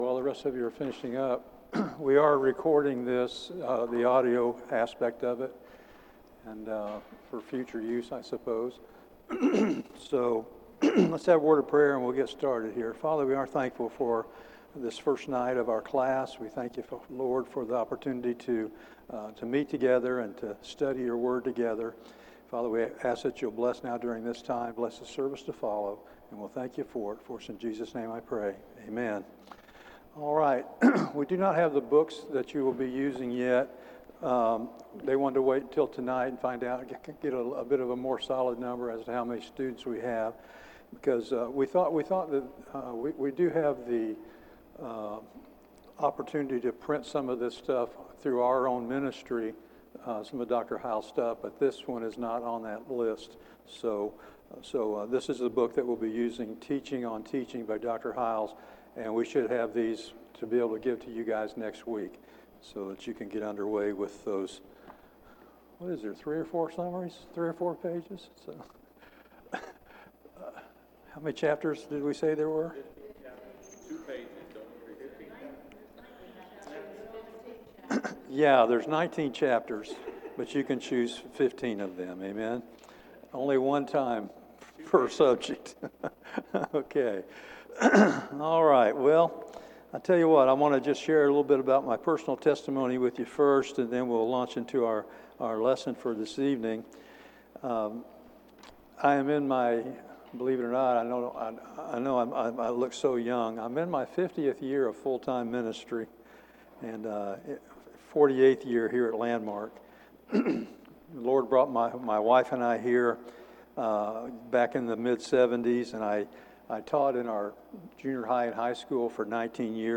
Service Type: Institute